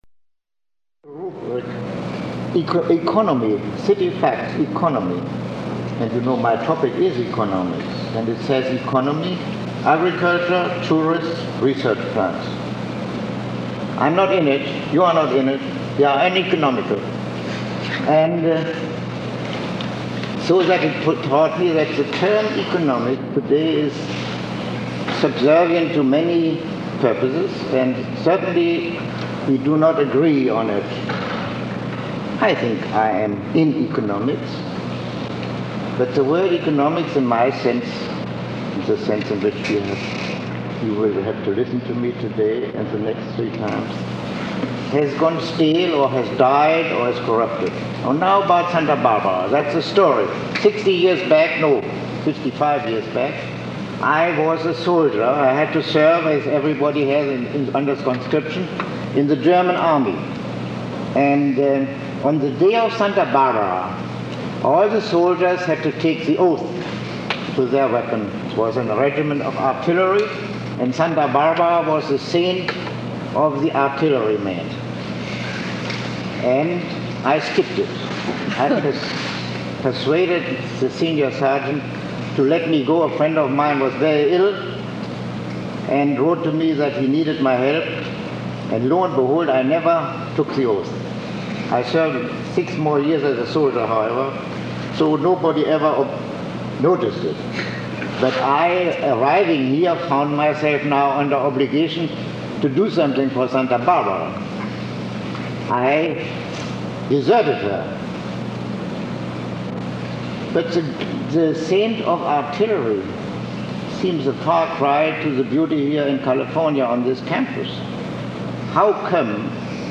Lecture 01